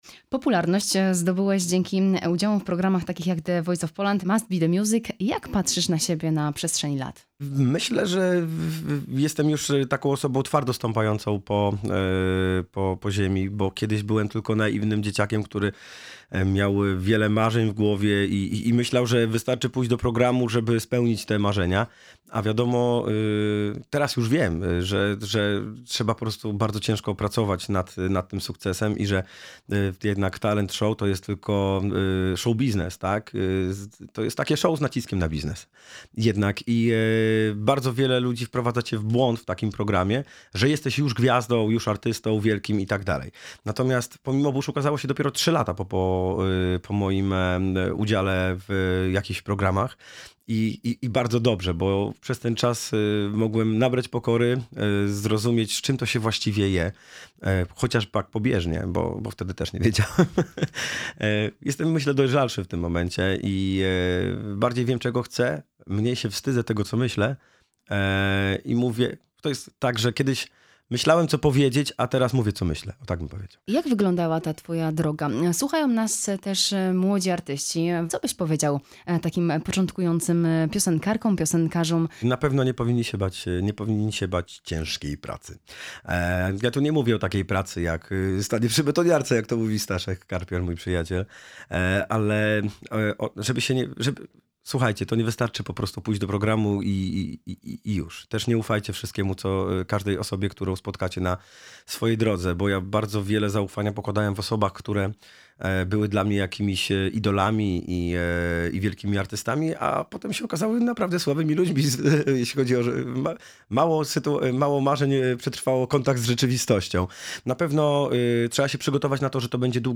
Cała rozmowa: